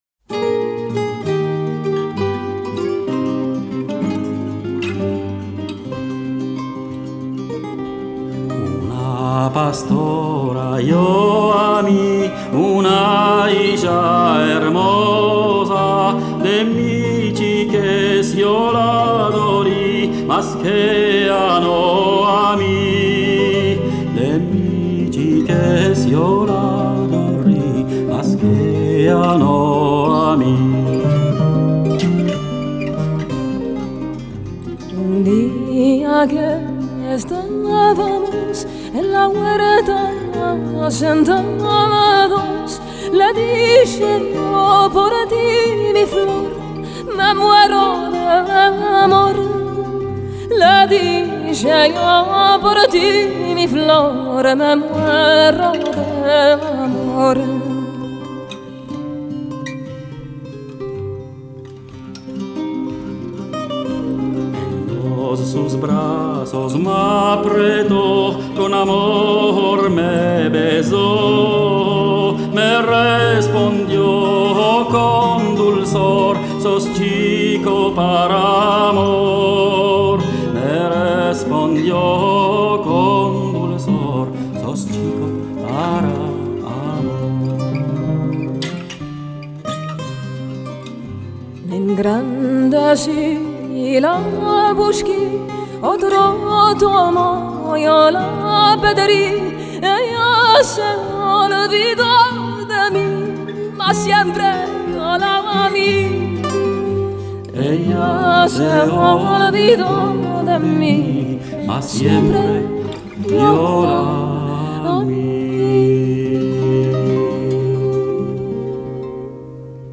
Genre: Ladino